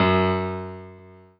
piano-ff-22.wav